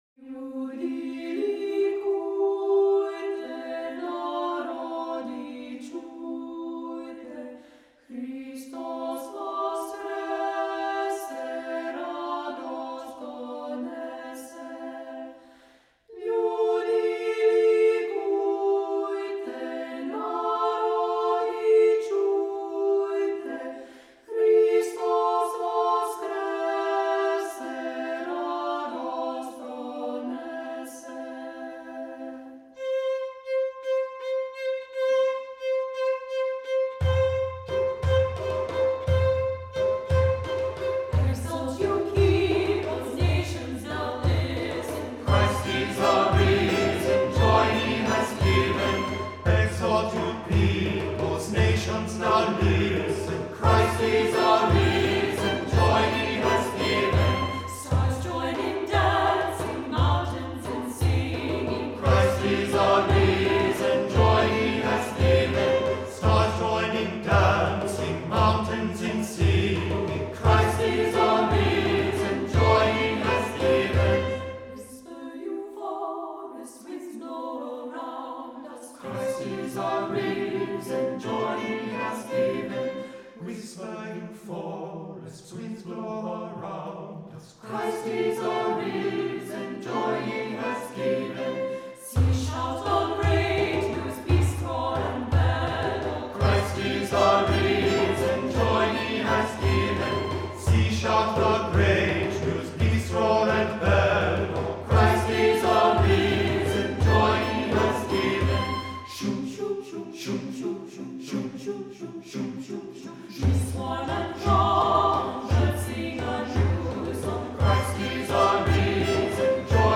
Voicing: SATB,Assembly